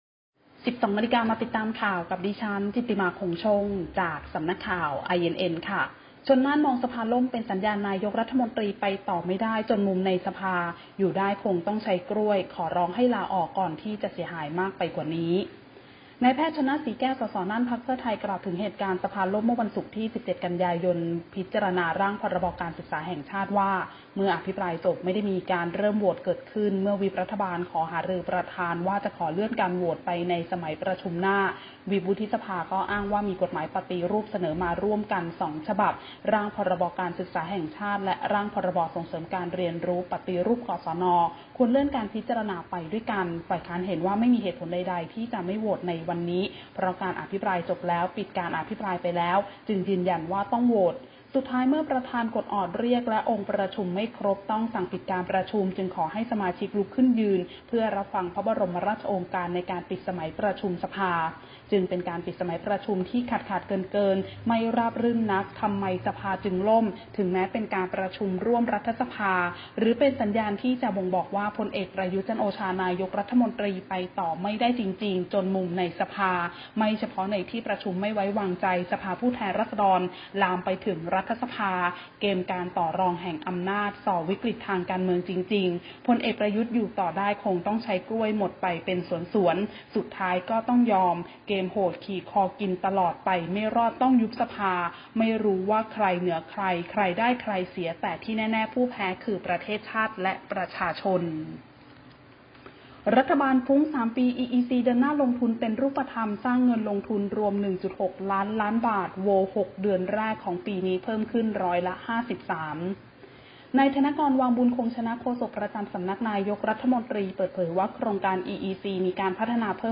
คลิปข่าวต้นชั่วโมง
ข่าวต้นชัวโมง 12.00 น.